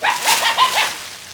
foxshock.wav